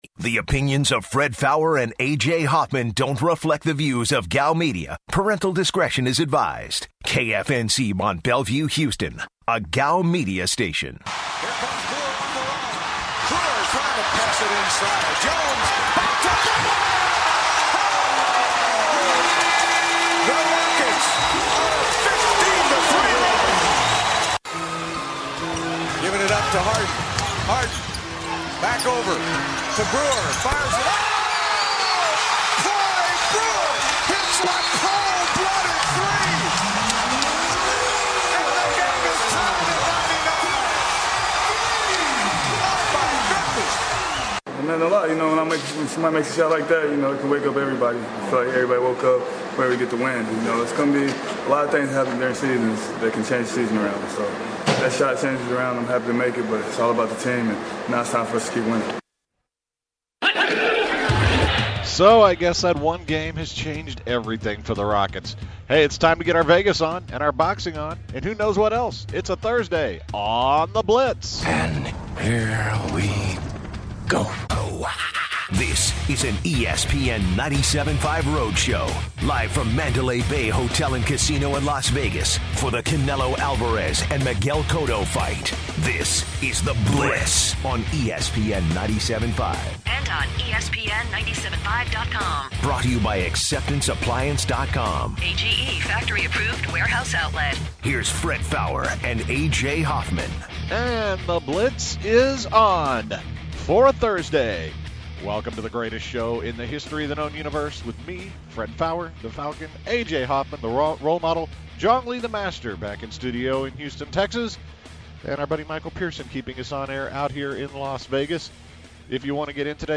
Live from Vegas, it's The Blitz! The guys open the show venting out their traveling woes. Plus, they interview professional boxer Bernard Hopkins.
Also, the guys have the pleasure of interviewing boxing legend Freddie Roach.